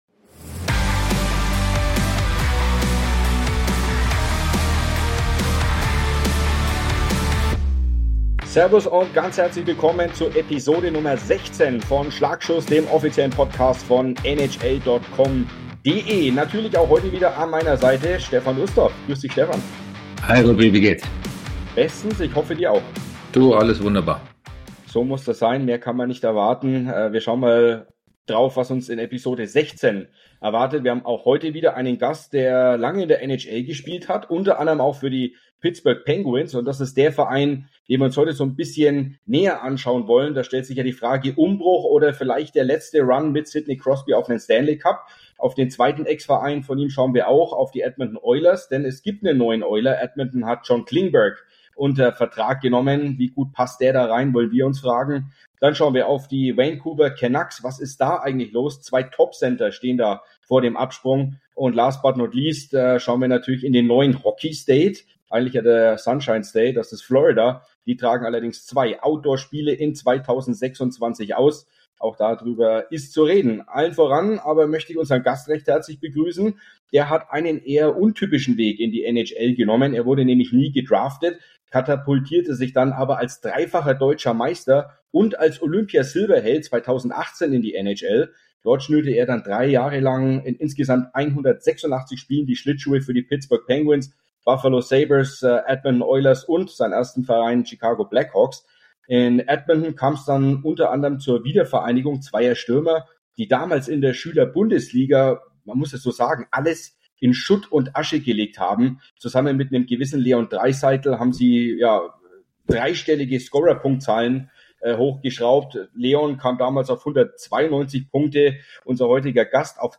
Podcast-Gast Dominik Kahun lässt die Hörer tief in seine Erlebnisse in der NHL eintauchen, spricht über sein Vorbild Sidney Crosby, die Wiedervereinigung mit Leon Draisaitl und seine Ex-Klubs Chicago Blackhawks, Pittsburgh Penguins und Edmonton Oilers.